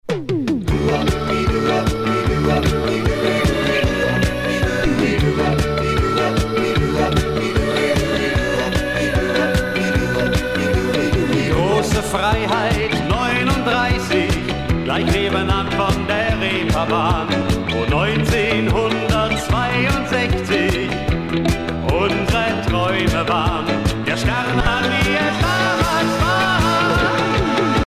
danse : twist
Pièce musicale éditée